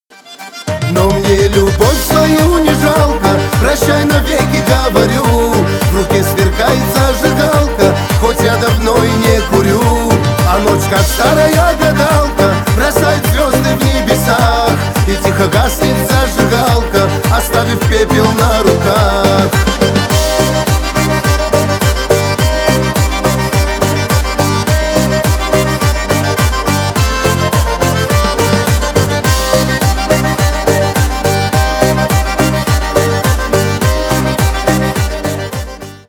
Шансон
кавказские # весёлые